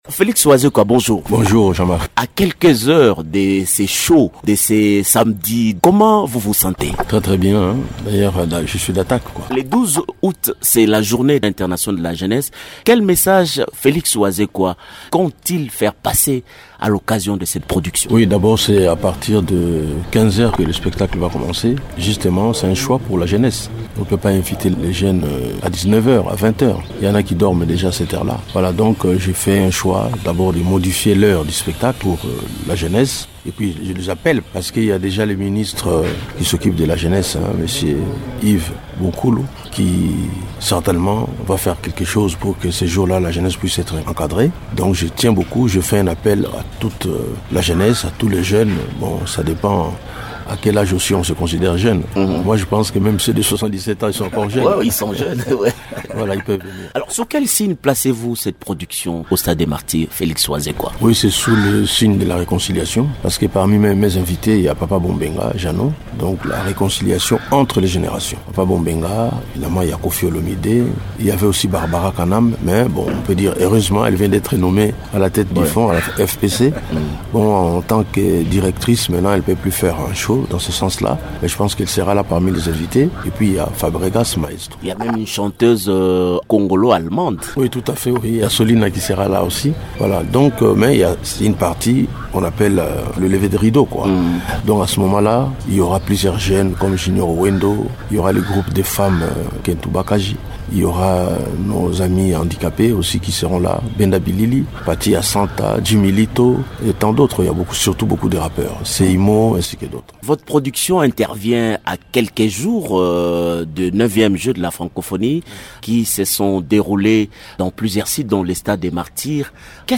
Félix Wazekwa s’entretient